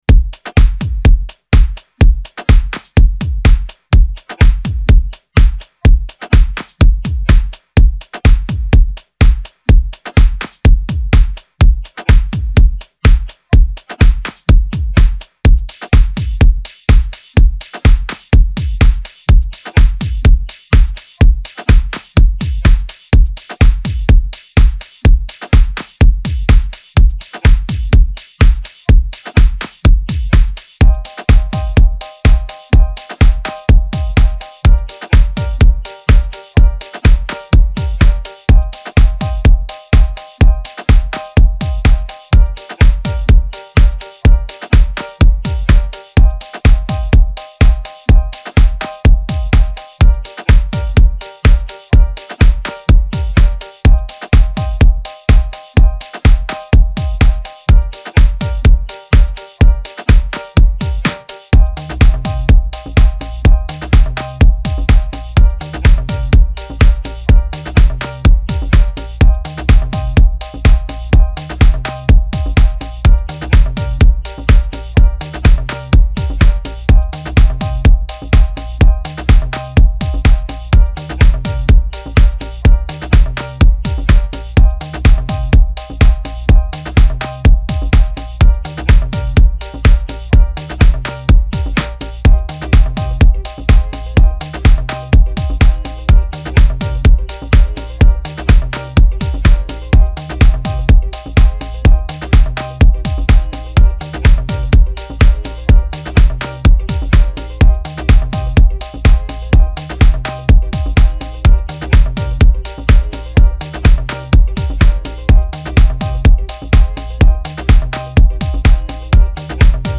Genre: Deep House.